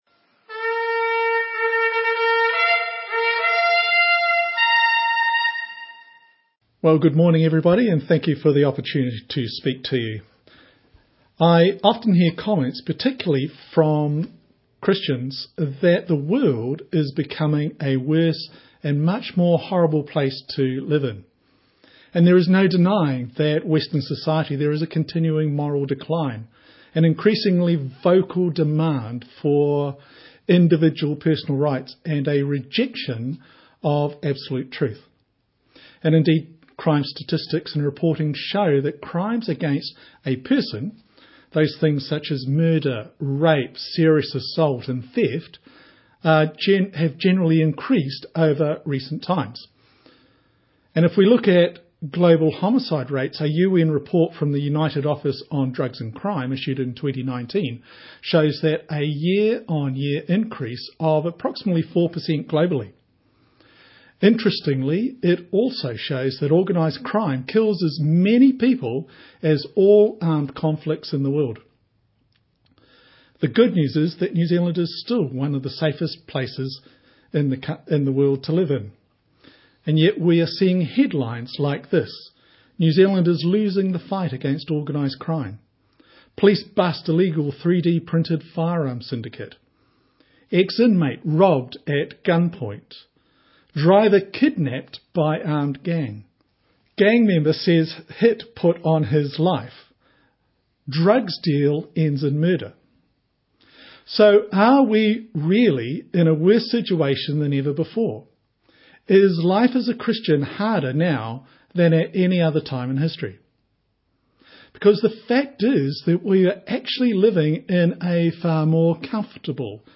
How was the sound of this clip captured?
Will we obey & will we respond to the opportunities that God presents to share the truth of the gospel? Online Sunday Morning Service 27th April, 2025 Slides from this service are below.